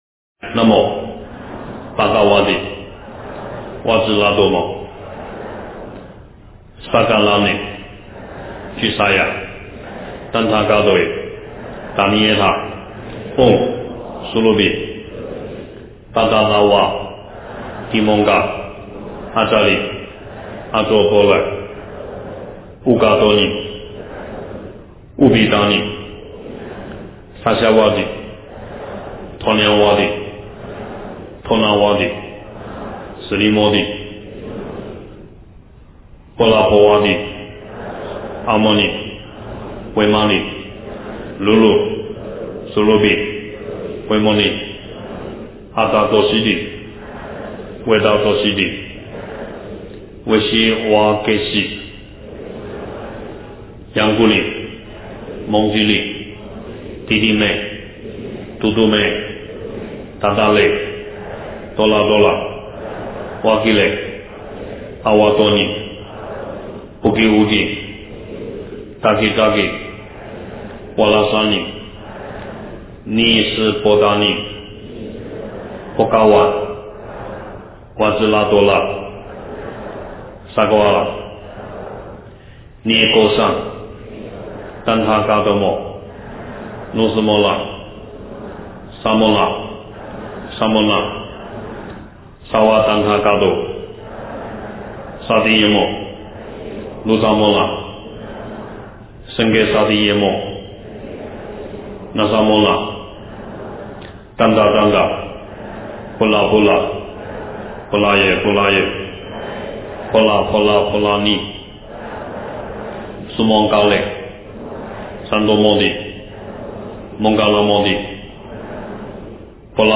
佛音 经忏 佛教音乐 返回列表 上一篇： 大方广佛华严经华严普贤行愿忏-上--僧团 下一篇： 阿弥陀经--悟因法师率众 相关文章 南无消灾延寿药师--未知 南无消灾延寿药师--未知...